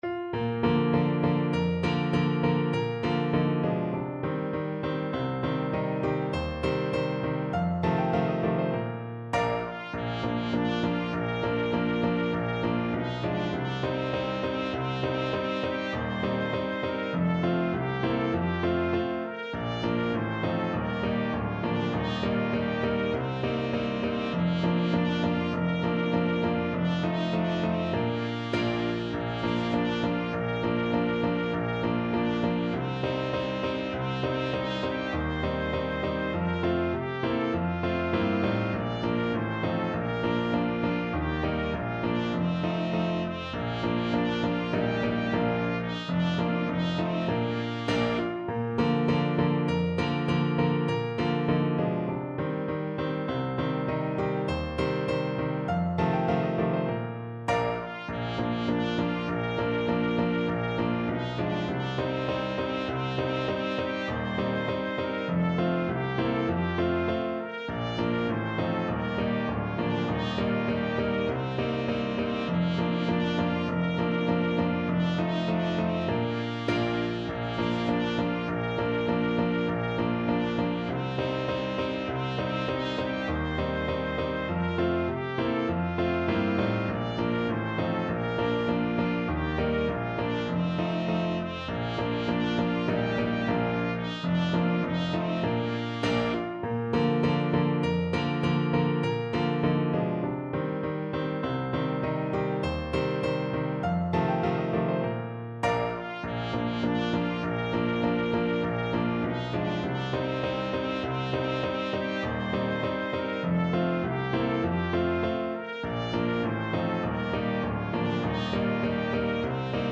Trumpet version
Moderato
2/2 (View more 2/2 Music)
Classical (View more Classical Trumpet Music)